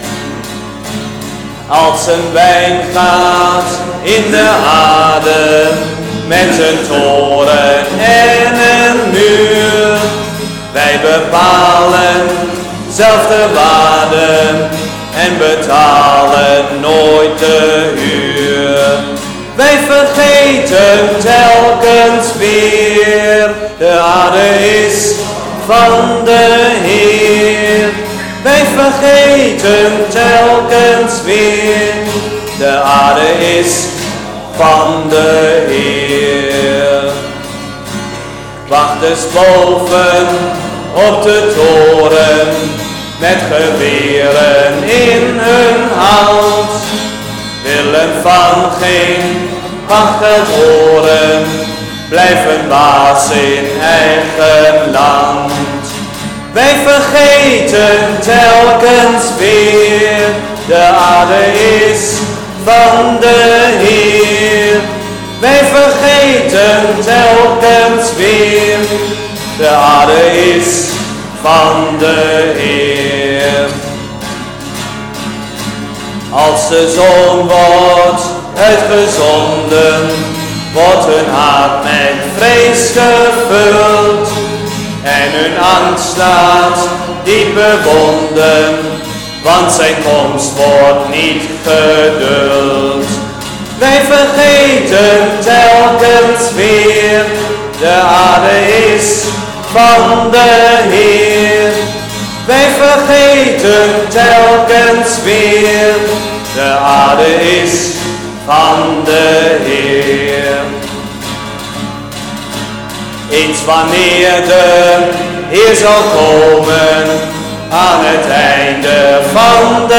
tijdens de ochtenddienst